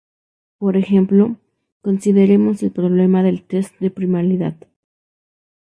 Uitgespreek as (IPA)
/ˈtest/